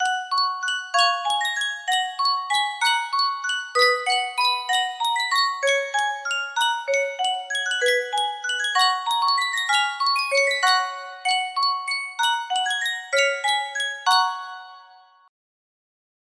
Yunsheng Music Box - Furusato 1597 music box melody
Full range 60